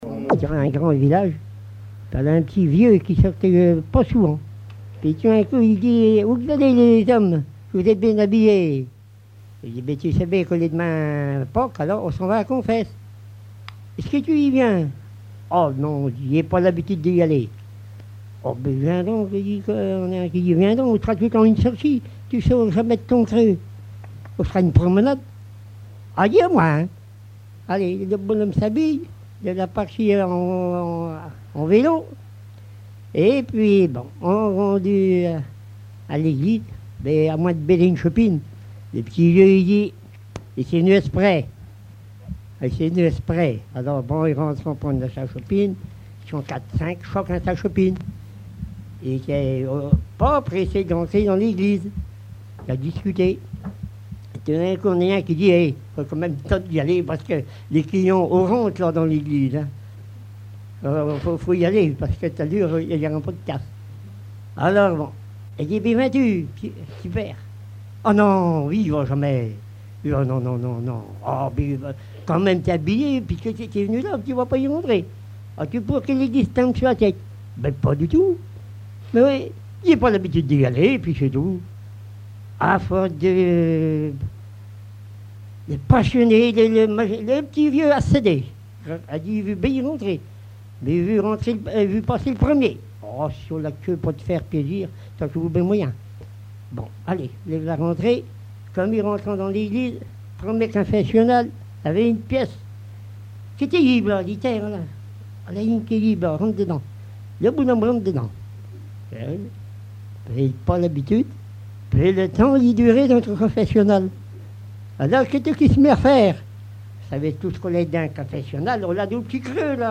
Langue Patois local
Genre sketch
Alouette FM numérisation d'émissions par EthnoDoc